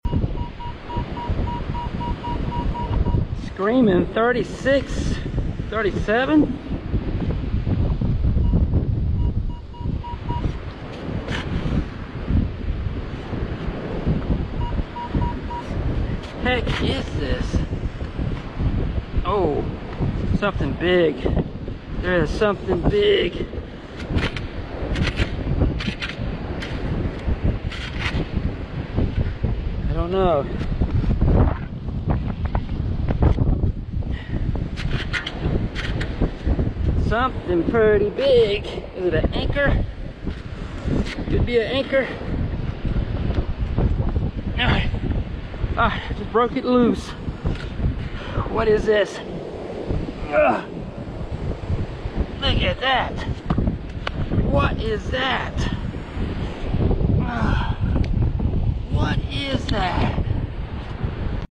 Found something pretty big deep down in the sand while I was metal detecting at the beach with a Minelab Equinox 800 Metal Detector